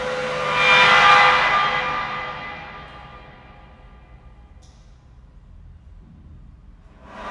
描述：在Baschet Sound Sculpture中演奏弓，木和金属的声音